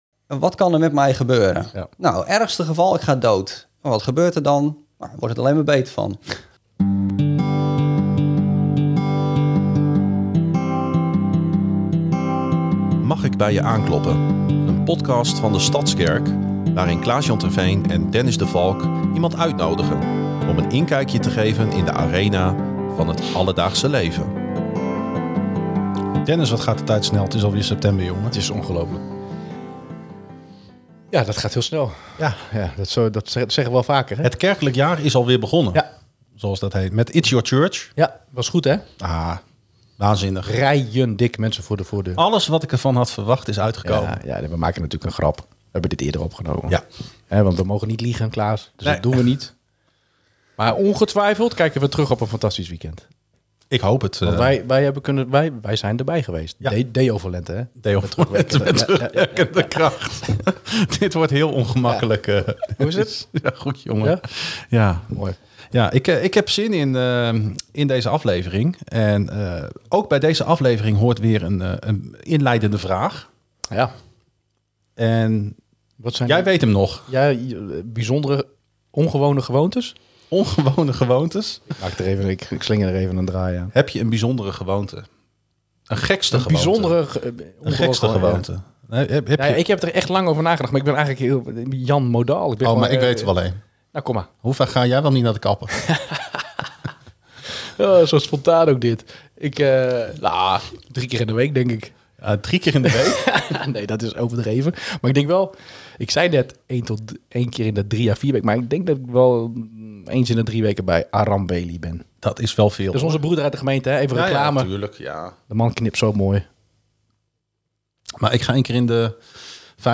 De gastheren kloppen aan bij gemeenteleden en gasten om samen in gesprek te gaan.